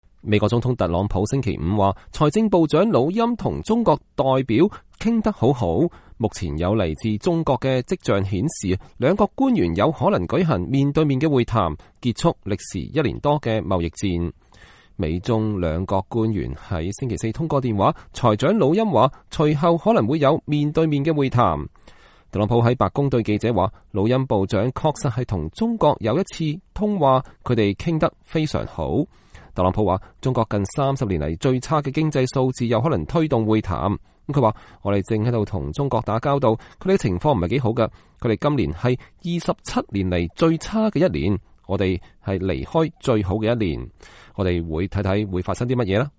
特朗普總統在離開白宮前往他在新澤西州貝德敏斯特的高爾夫度假地前接受記者採訪。(2019年7月19日)